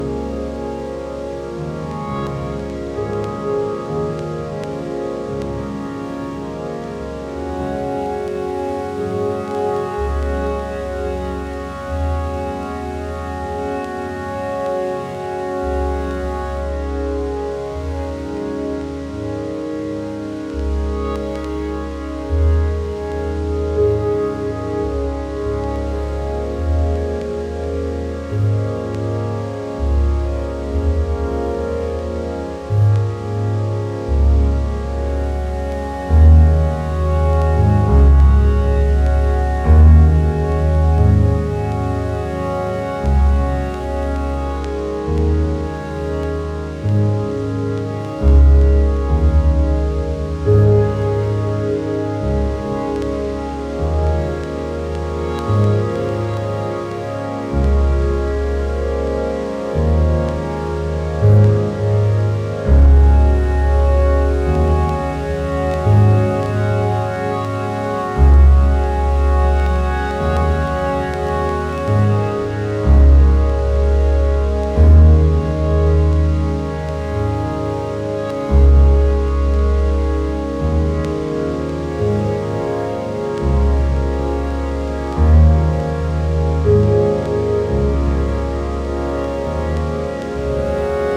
Electronix Ambient